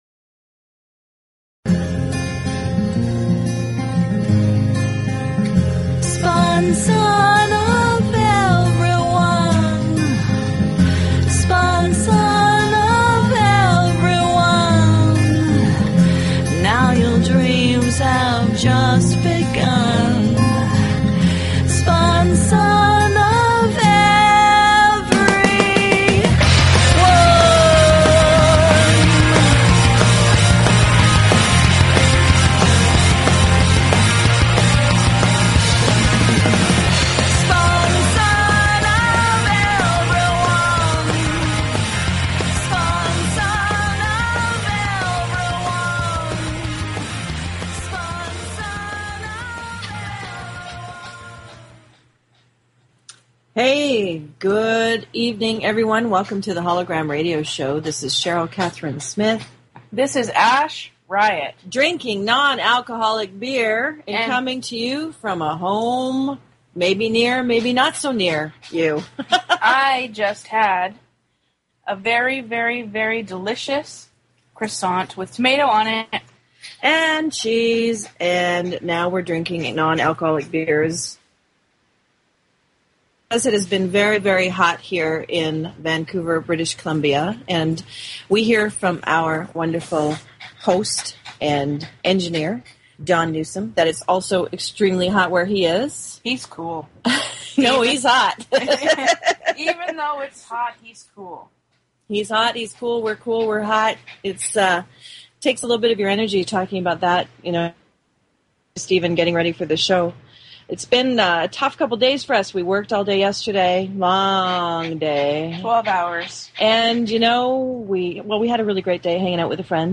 Talk Show Episode, Audio Podcast, Hollow-Gram_Radio_Show and Courtesy of BBS Radio on , show guests , about , categorized as
They offer a unique radio show where they share their music, their sharp wit, and their visions for This planet.